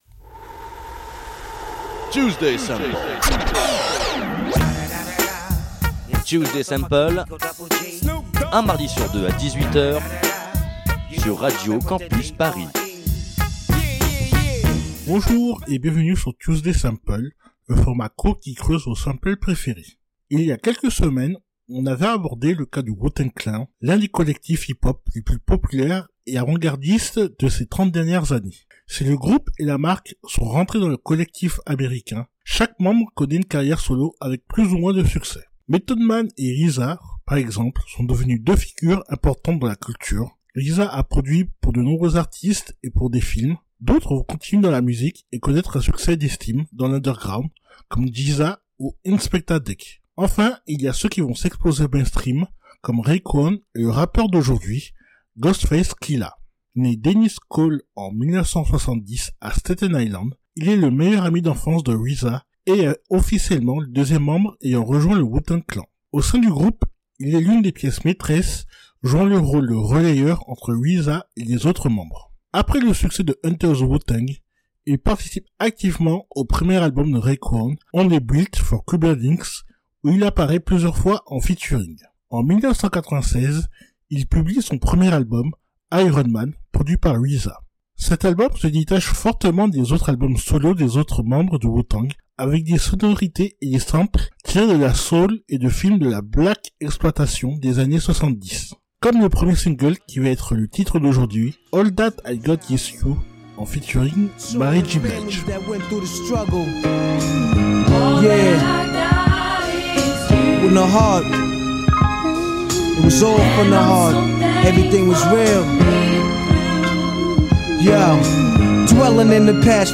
Type Musicale Hip-hop